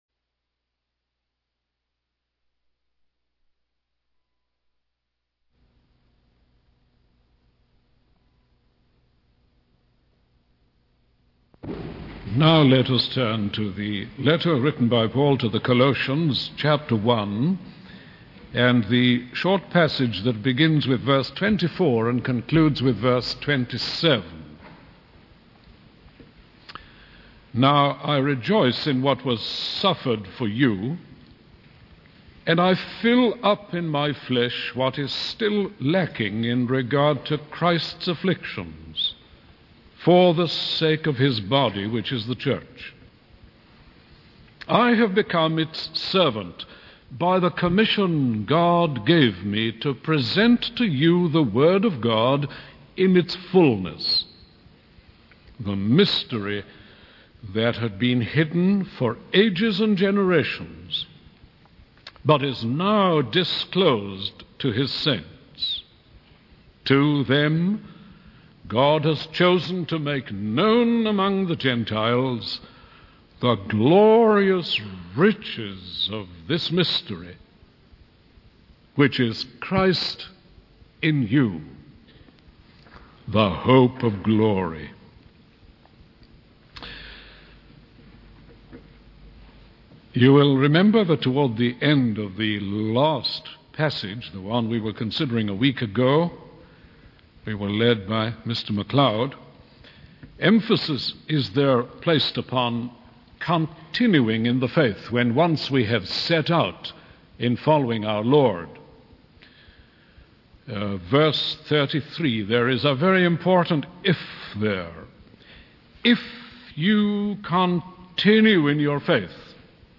In this sermon, the speaker discusses the power of joy and sorrow in our lives. He emphasizes that these emotions can either lead to self-indulgence or self-sacrifice.